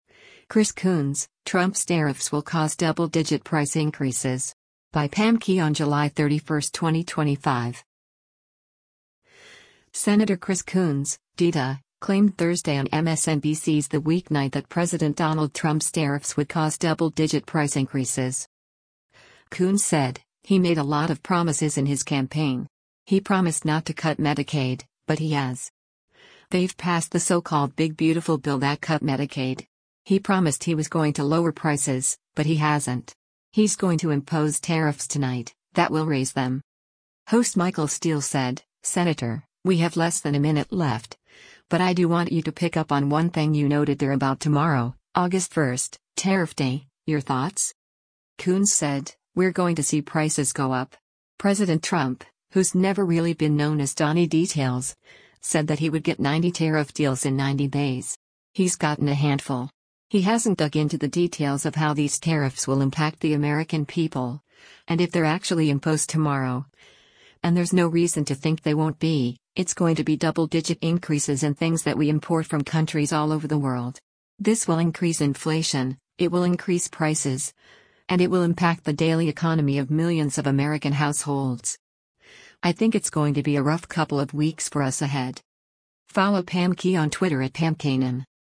Senator Chris Coons (D-DE) claimed Thursday on MSNBC’s “The Weeknight” that President Donald Trump’s tariffs would cause “double-digit” price increases.